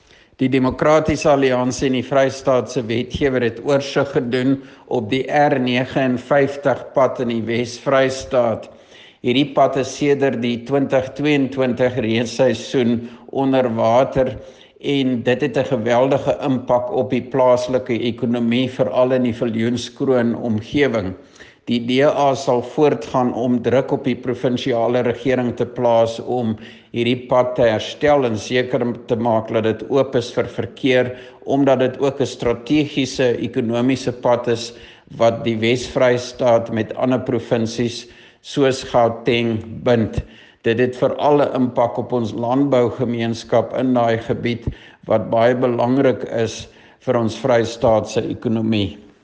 Afr-voice-14.mp3